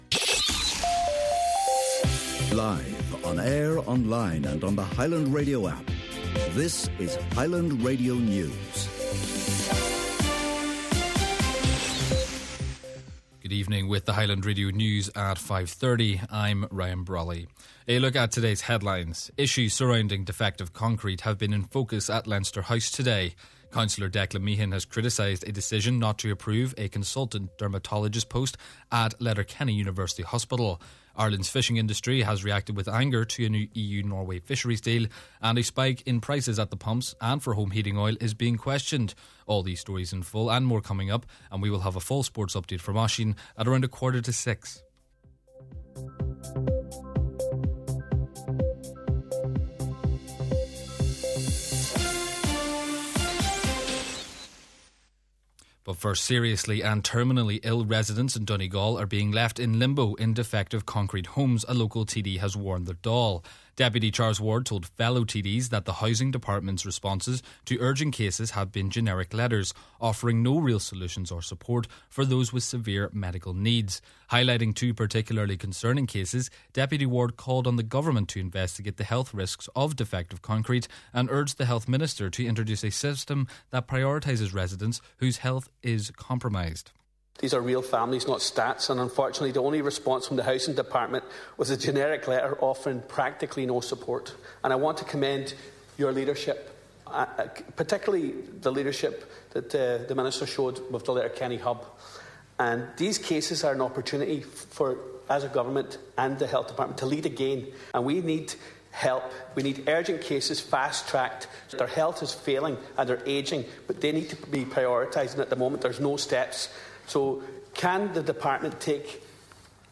Main Evening News, Sport, Farming News and Obituary Notices – Thursday March 5th